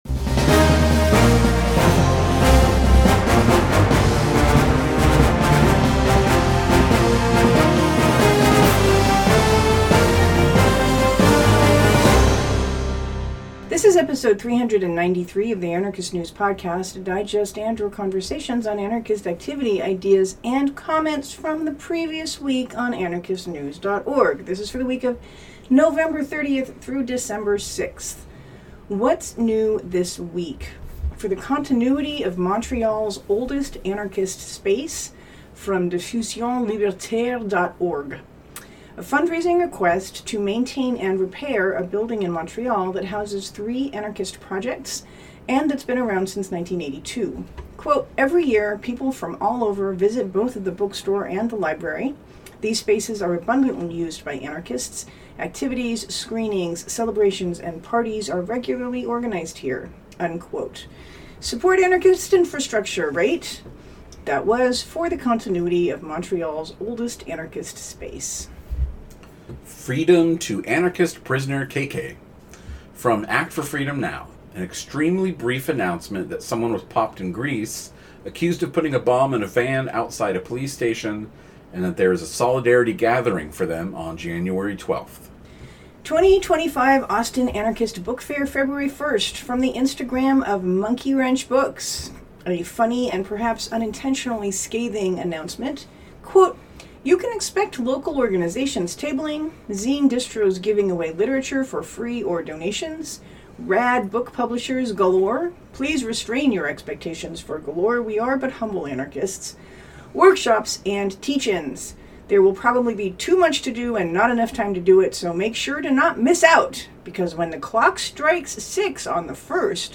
ANews official intro triumphant horns The Go!